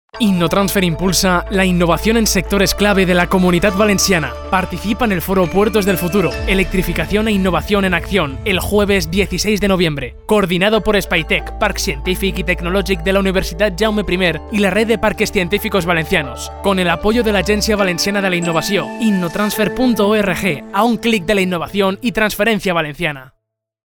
Espaitec Innotransfer Cuña Puertos Noviembre 2023 - Espaitec